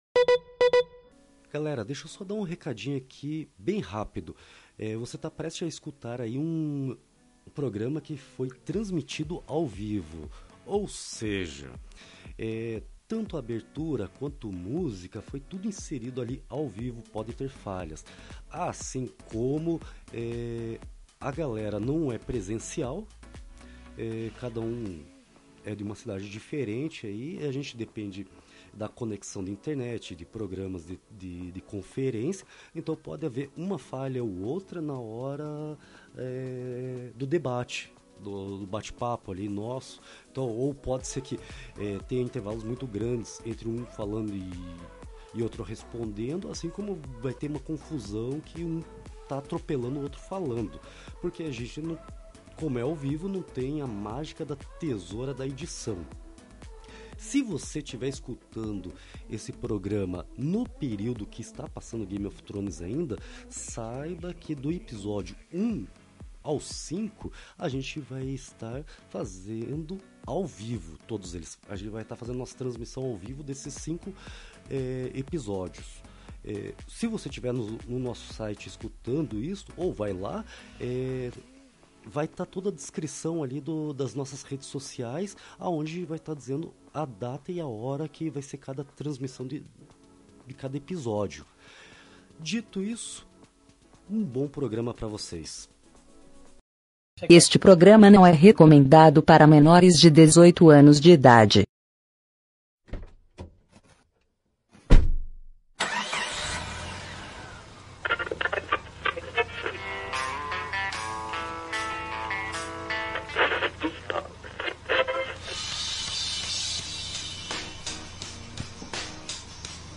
Hoje estamos ao vivo para debater nossas impressões sobre o primeiro episódio da última temporada de Game of Thrones.